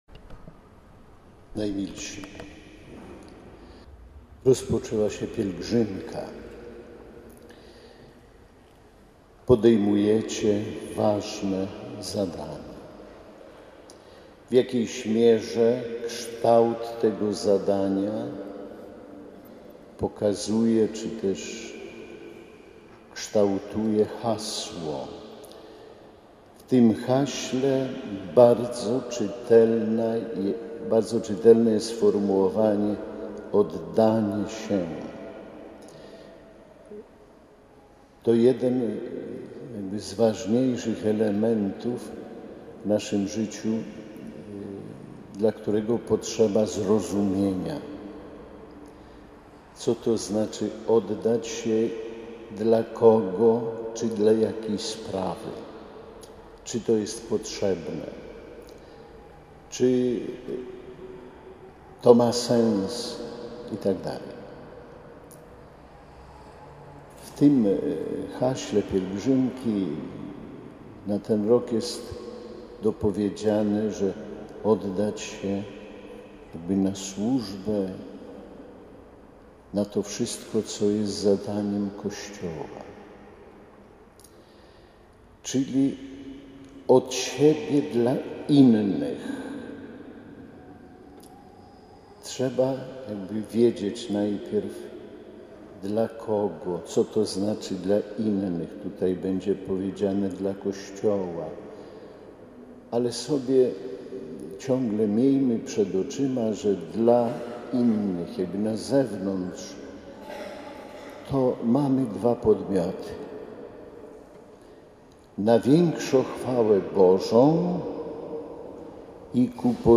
Mszą świętą w konkatedrze na Kamionku rozpoczęła się XL Praska Pielgrzymka Pomocników Maryi Matki Kościoła. Przewodniczył jej biskup Romuald Kamiński. W homilii nawiązał do hasła pielgrzymki „Oddani Maryi za Kościół Chrystusowy”.
Tak rozumiane oddawanie się to najbardziej czytelny wyraz miłości – powiedział w homilii biskup: